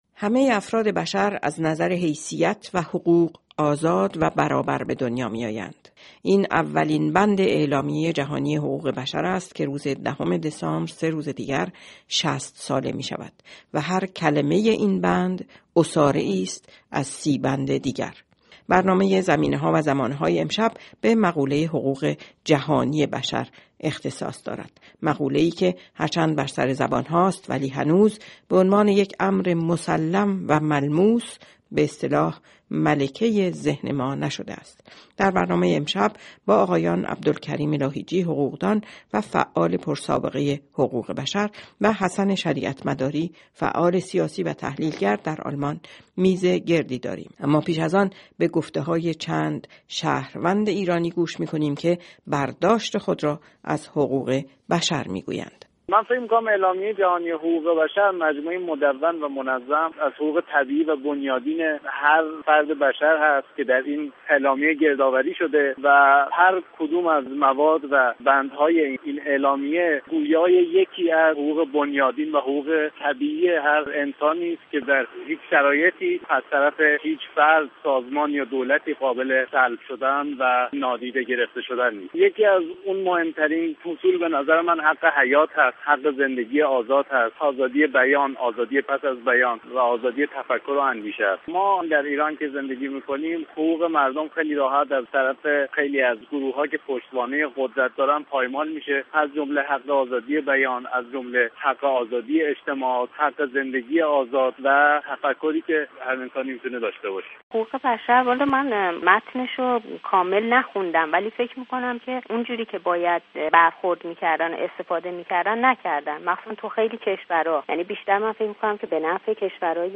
این میزگرد رادیوئی را بشنوید